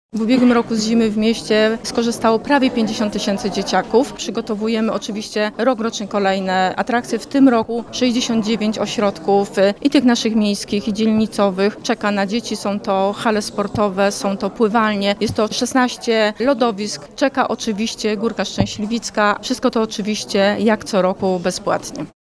Mówi wiceprezydent Warszawy Renata Kaznowska.